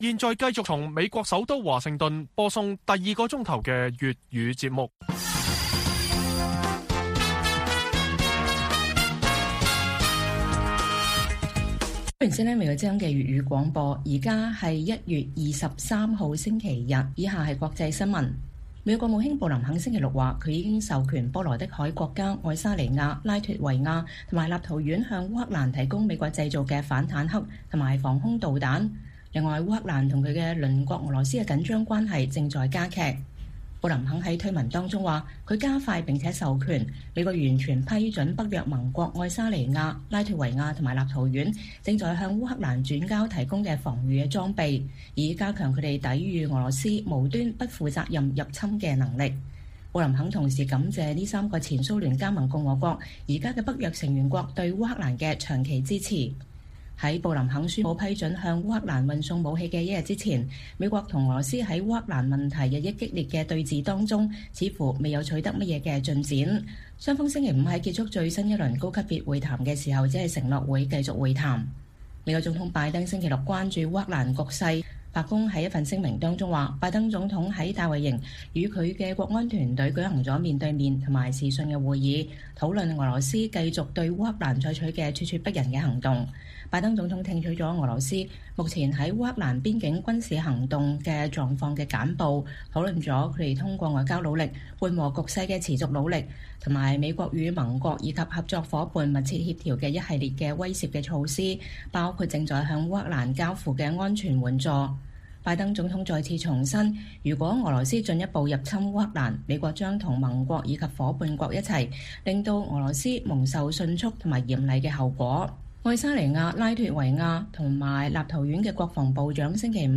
粵語新聞 晚上10-11點: 羅斯烏克蘭法國德國將在巴黎討論烏克蘭危機